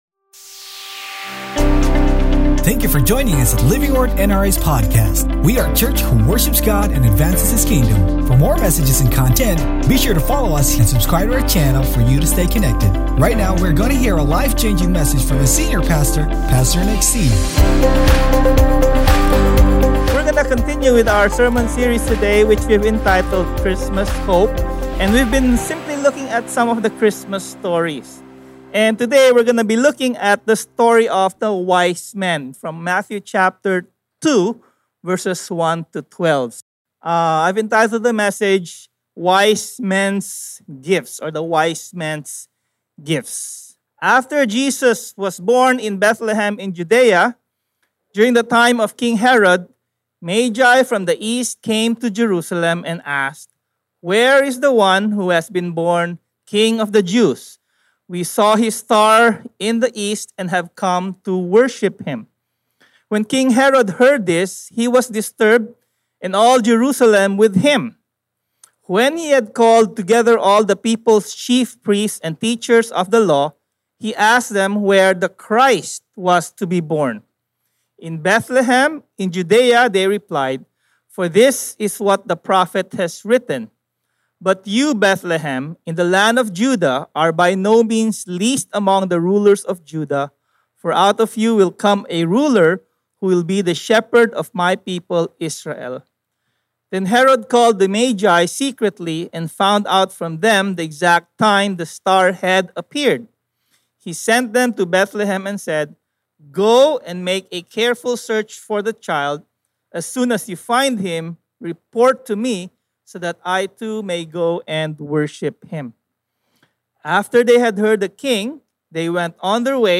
Sermon Title: WISE MEN’S GIFTS